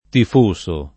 tifoso [ tif 1S o ]